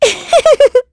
Estelle-Vox_Happy1.wav